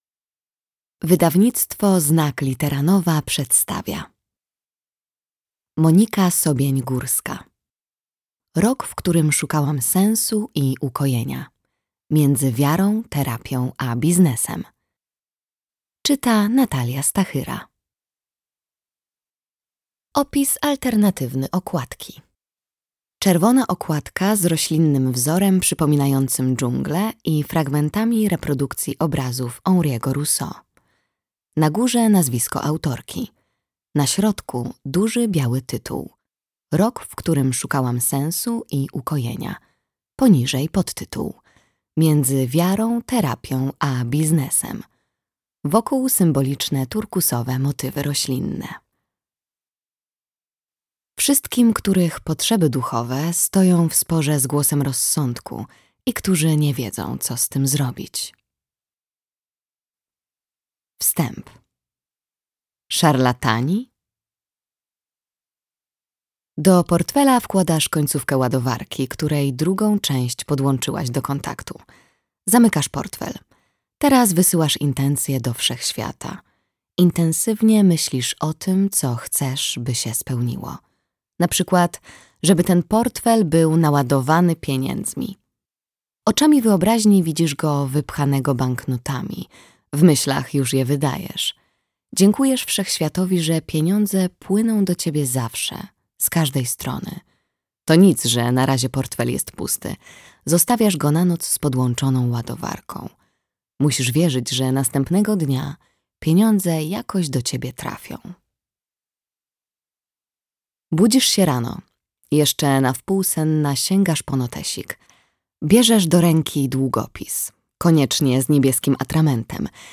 Audiobook + książka Rok, w którym szukałam sensu i ukojenia.